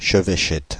Ääntäminen
Ääntäminen France (Île-de-France): IPA: /ʃə.vɛ.ʃɛt/ Haettu sana löytyi näillä lähdekielillä: ranska Käännös Substantiivit 1.